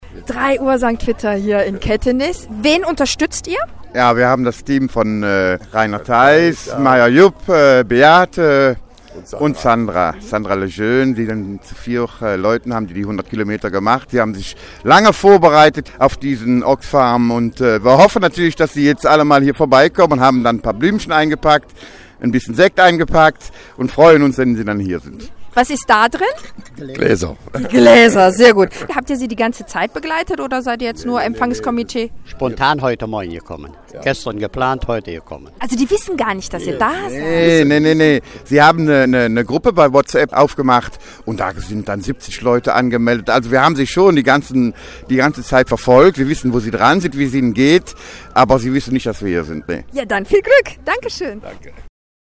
Eindrücke von der Oxfam Trailwalker Zielankunft